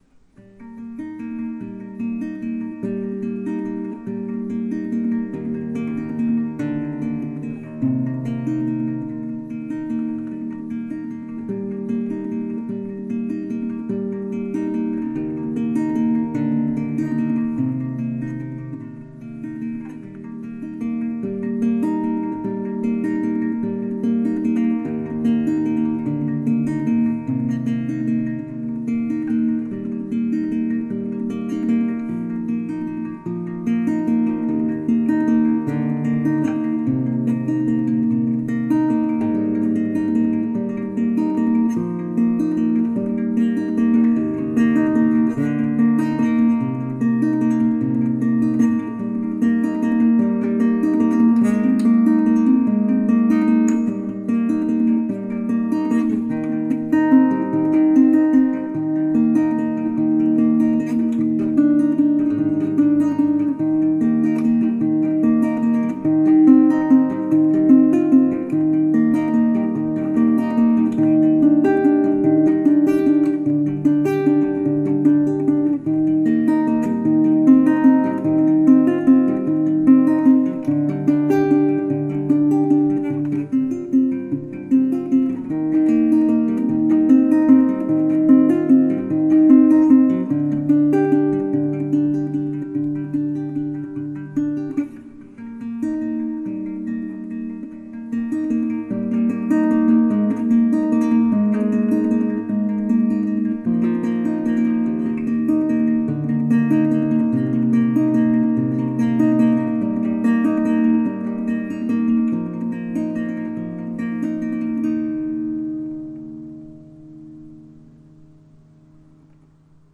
タグ: 映画、ギター、夢のような、クラシック